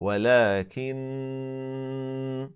VKTIGT: När reciteraren uttalar stressad nūn eller mīm, måste han/hon förlänga ghunnah mest fullständigt(1) (2) (غنةَ أكملَ ماَتكون), det vill säga när reciteraren både fortsätter och stannar.
Exempel på när man stannar: